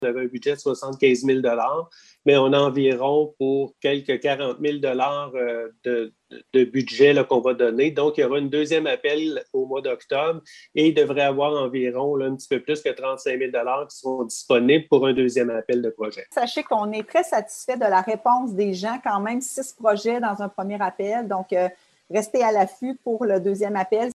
Le conseiller municipal Stéphane Biron, membre du comité de sélection, mentionne qu’un peu plus de 27 000$ restent disponibles pour un deuxième tour d’appel de projets. Écoutez M. Biron, suivi de la mairesse Geneviève Dubois